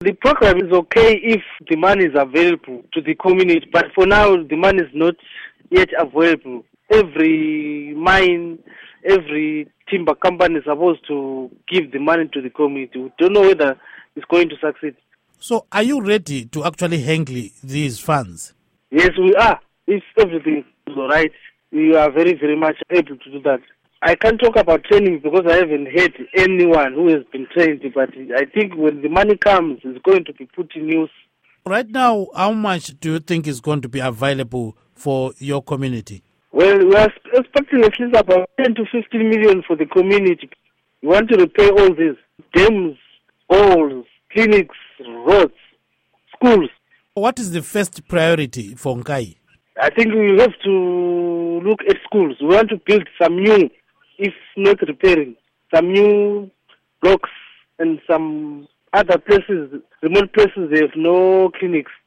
Interview With Chief Gilford Nkalakatha Ndiweni